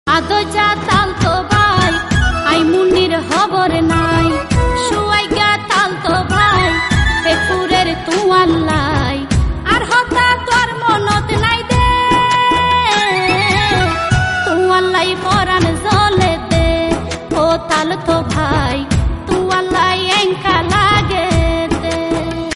আঞ্চলিক গান